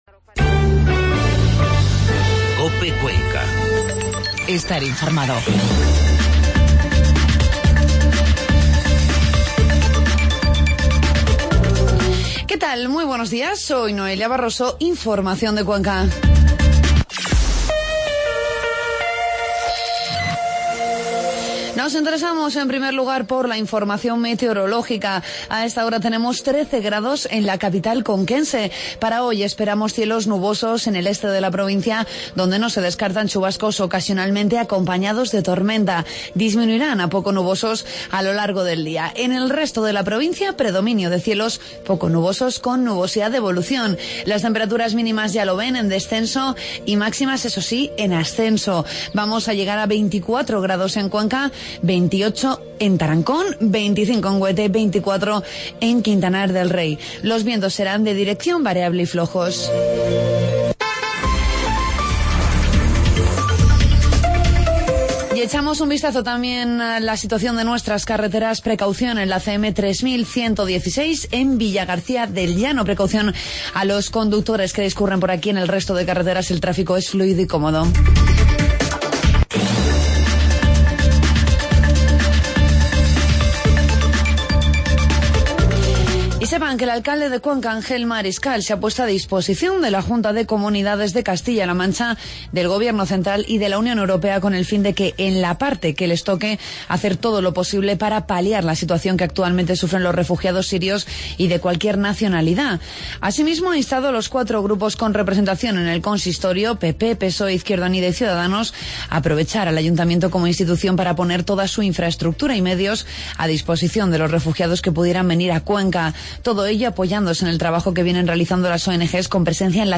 Informativo matinal 8 de septiembre 08.24